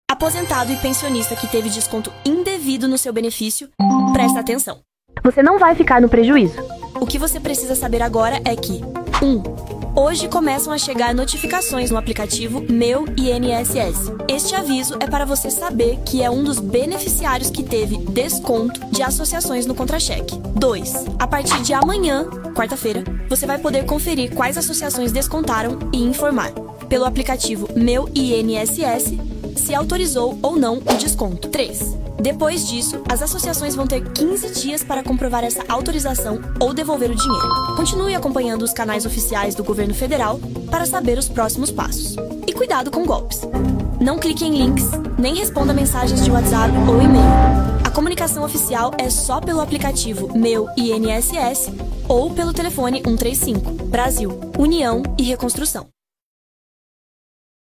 SPOT | Aposentados e pensionistas do INSS, atenção!